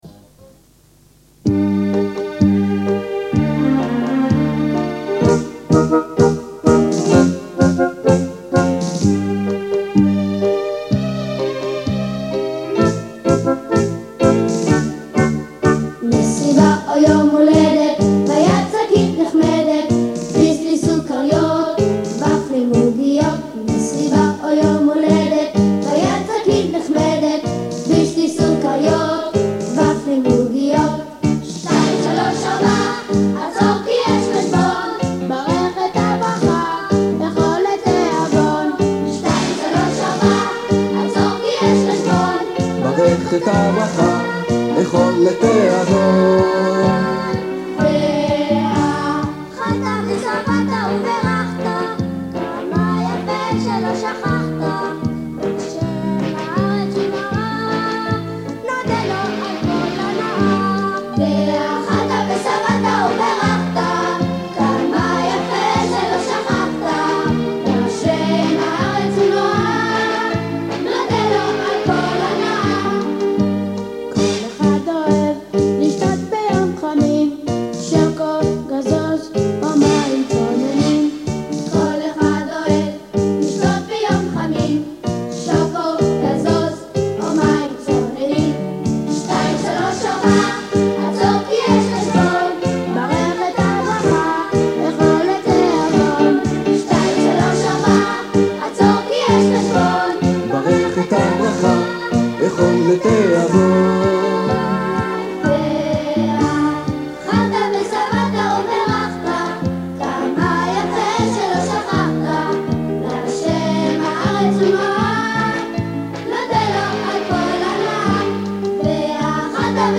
מקוה שזה יעזור לך לצערי את רואה מה השעה, הרגע הגעתי ואני צכה עוד להשאר ערה עד--- זה מה שמצאתי שדגום כבר למחשב ואין לי אחריות על איכות הדגימה.